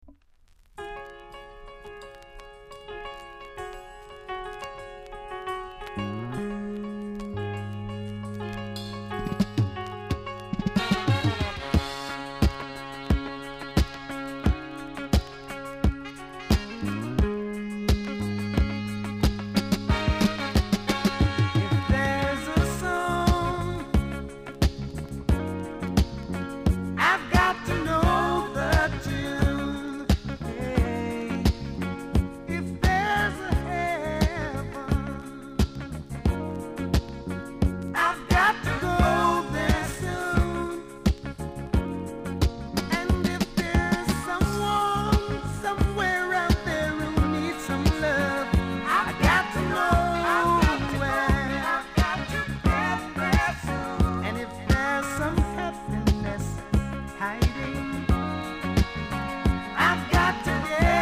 ※小さなチリノイズが少しあります。
コメント MELLOW ISLAND SOUL!!※裏面の後半で少しプチプチあります。